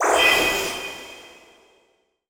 magic_light_bubble_04.wav